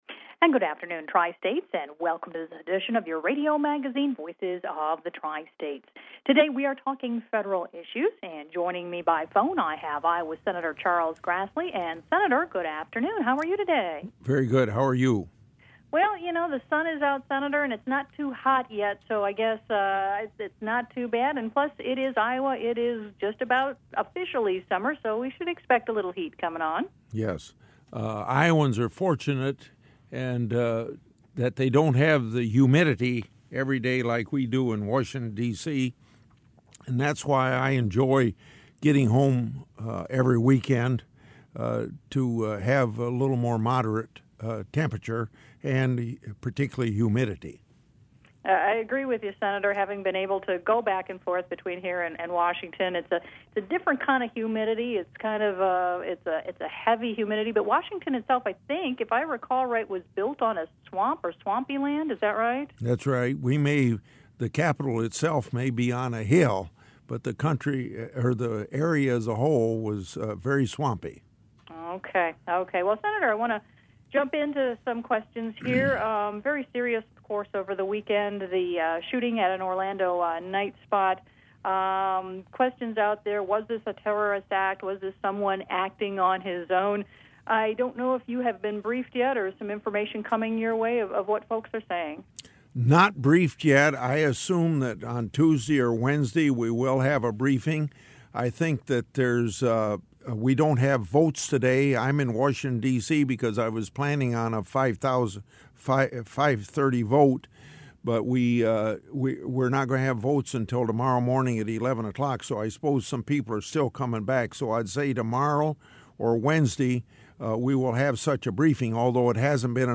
Public Affairs Program, KDTH.mp3